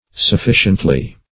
sufficiently - definition of sufficiently - synonyms, pronunciation, spelling from Free Dictionary
Sufficiently \Suf*fi"cient*ly\, adv.